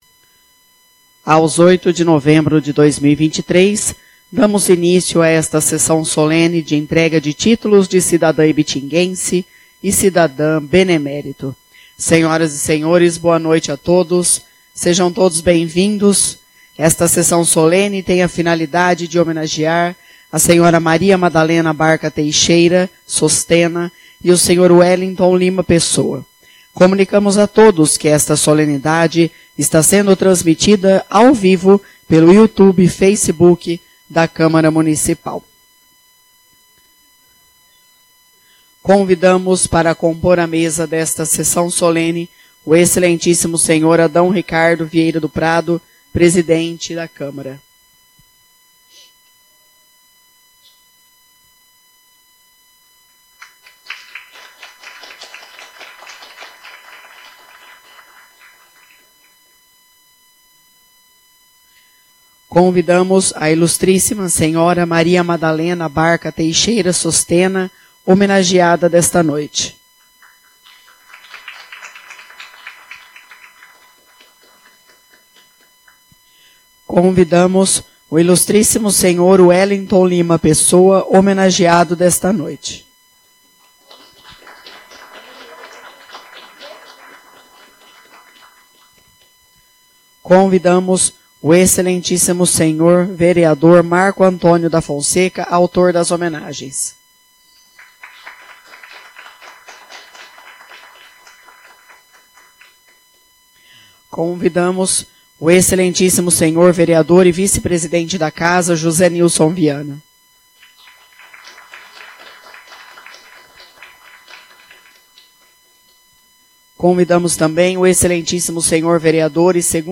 Sessões Solenes/Especiais
no auditorio do CIEI